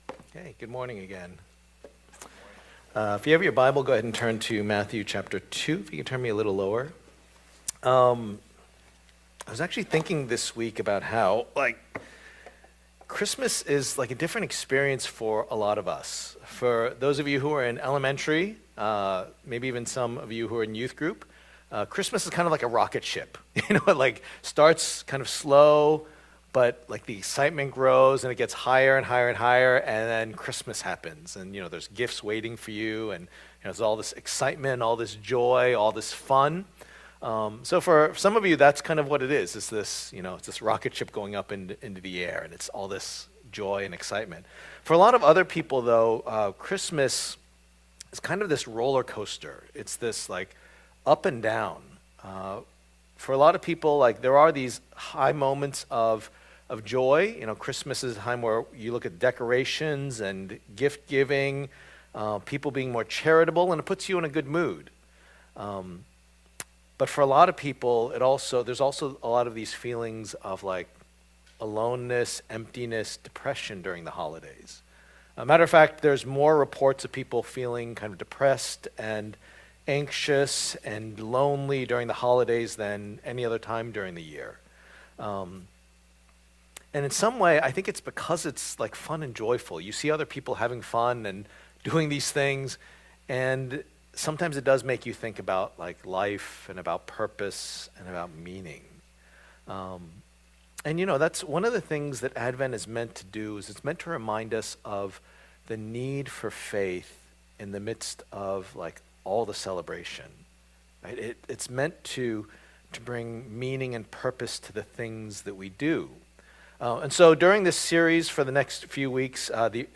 The Meaning in Jesus' Birth Passage: Matthew 2:1-12 Service Type: Lord's Day